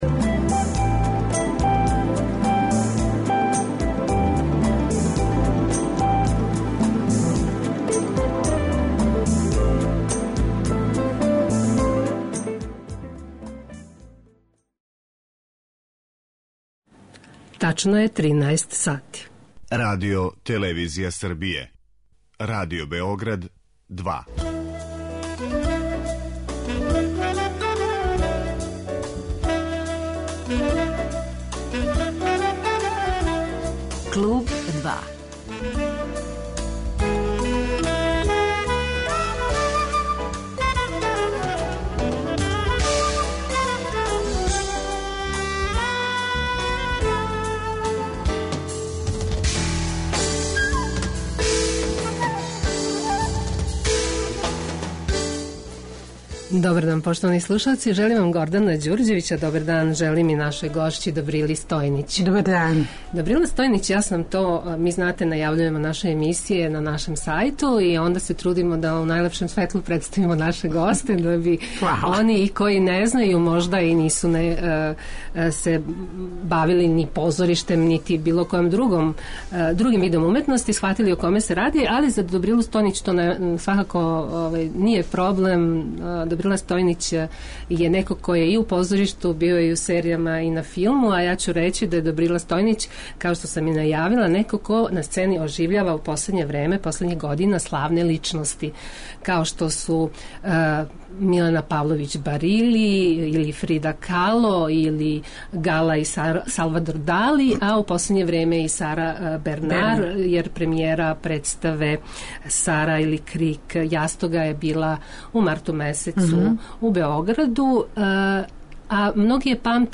Гошћа: Добрила Стојнић